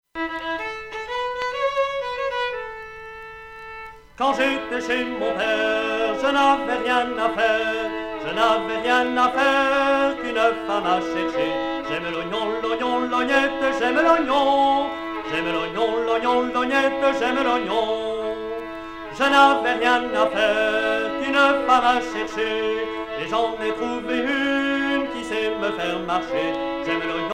circonstance : fiançaille, noce
Genre laisse
Pièce musicale éditée